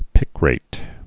(pĭkrāt)